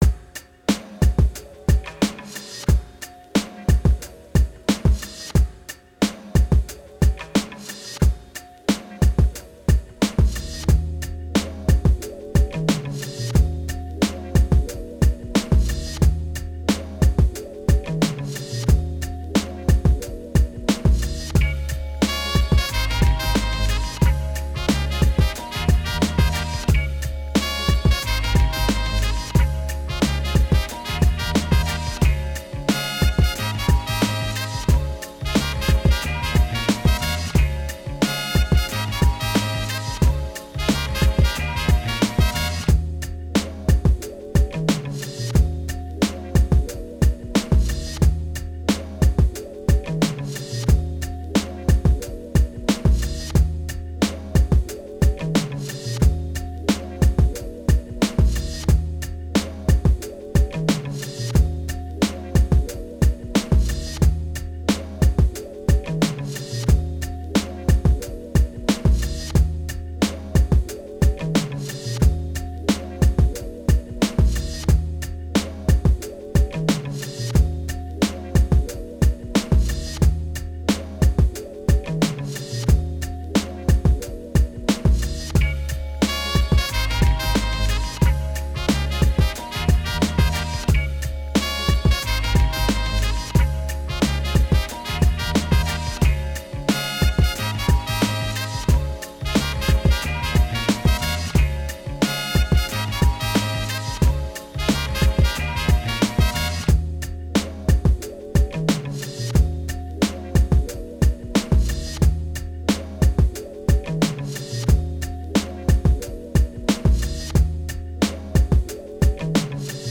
chill_vibez.ogg